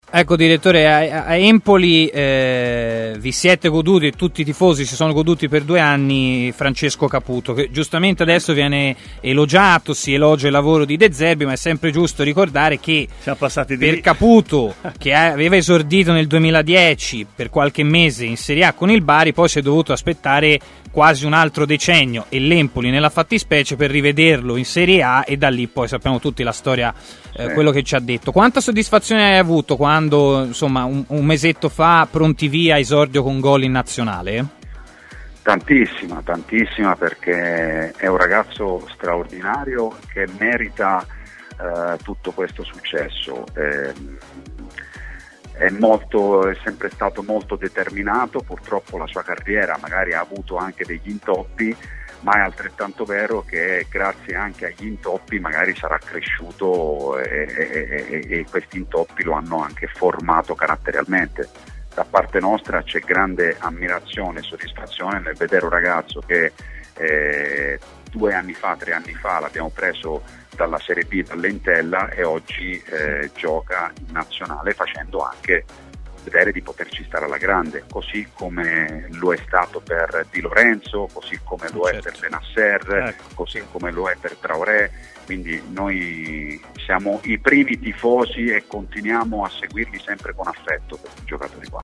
ha parlato a TMW Radio